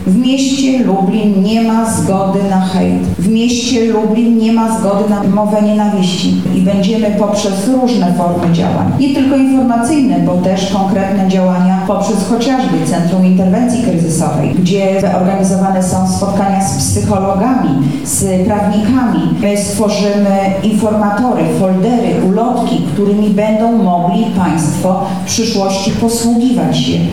anna augustyniak– mówi Anna Augustyniak, Zastępca Prezydenta Miasta Lublin ds. Społecznych.